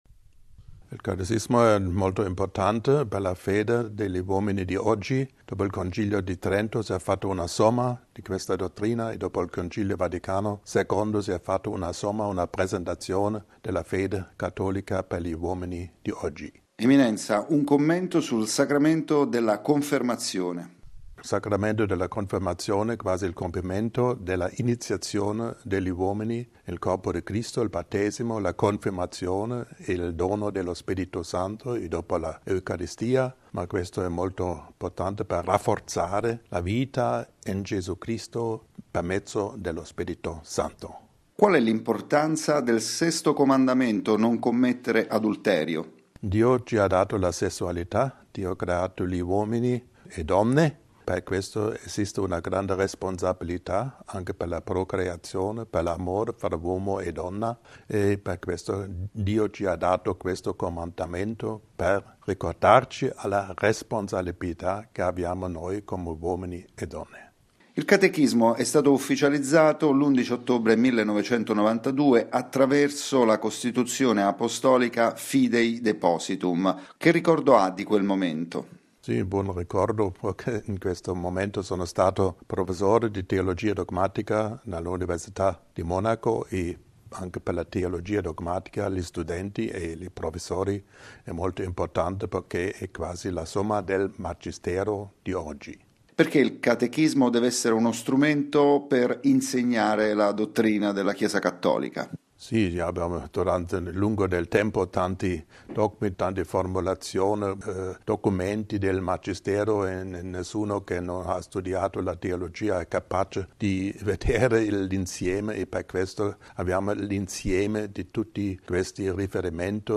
Ascolta l’intervista al cardinale Leonardo Sandri